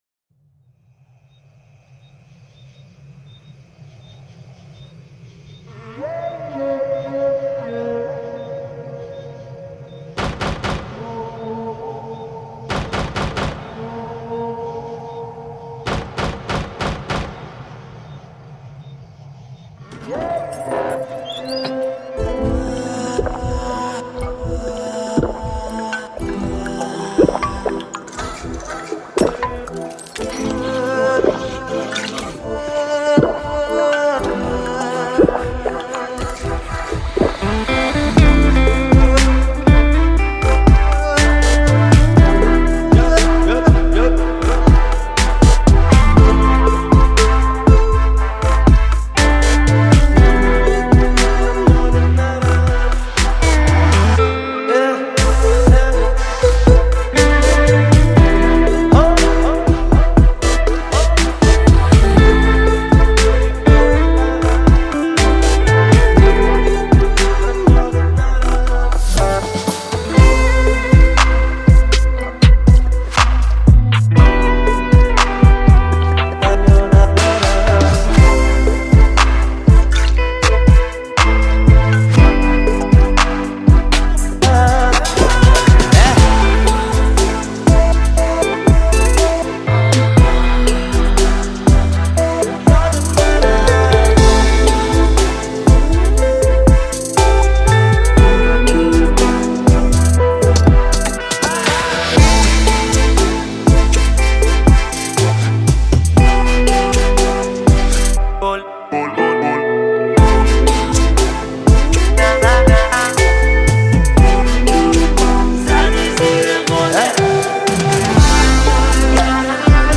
download-cloud دانلود نسخه بی کلام (KARAOKE)